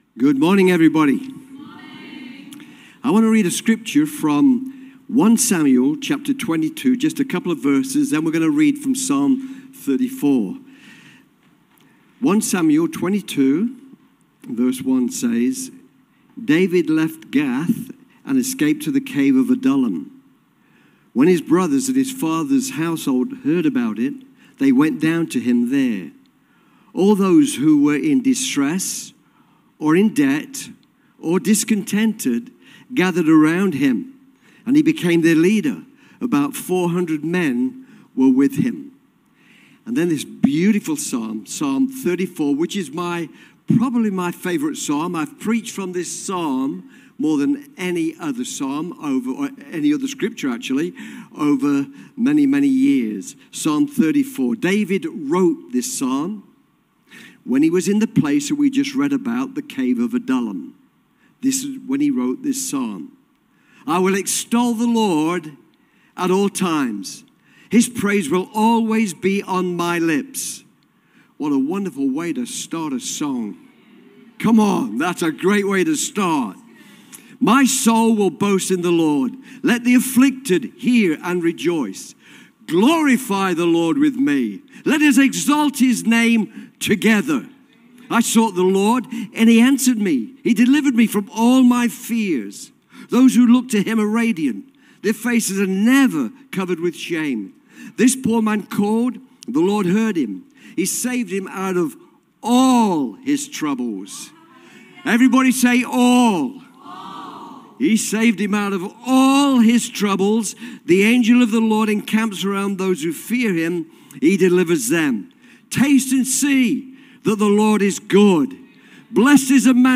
This sermon about Psalm 34